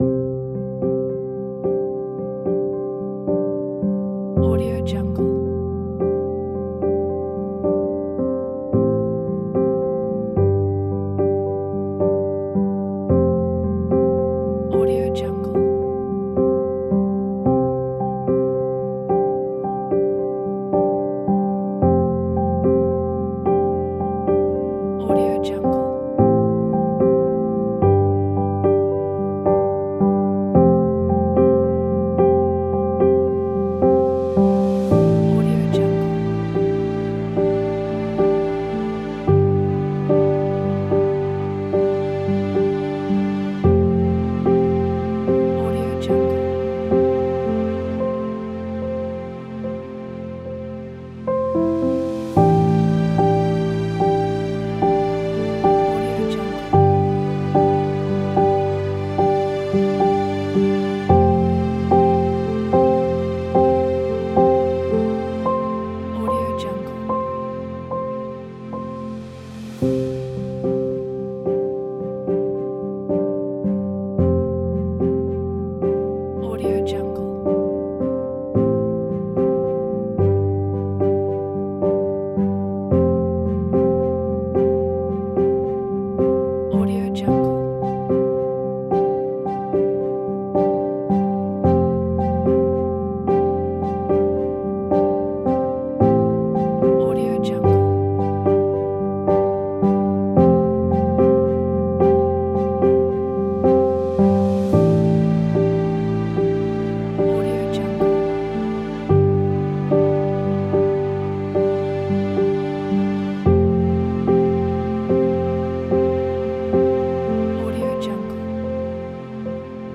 سینمایی